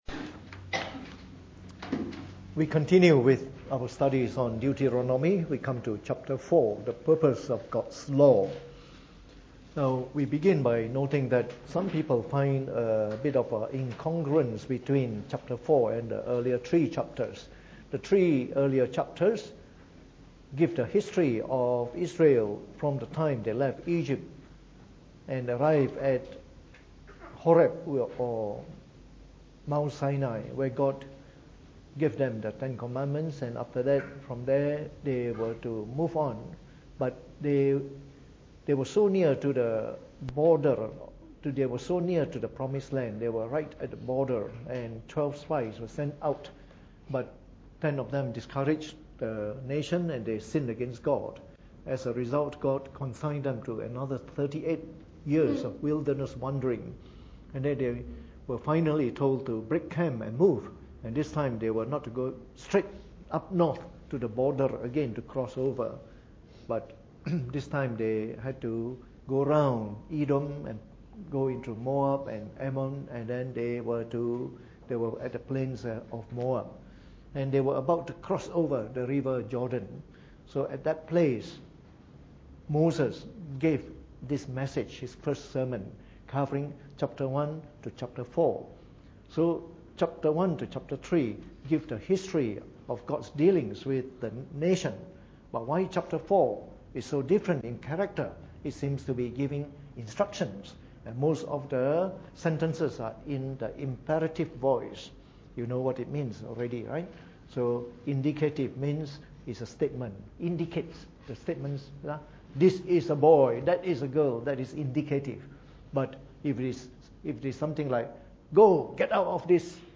Preached on the 31st of January 2018 during the Bible Study, from our series on the book of Deuteronomy.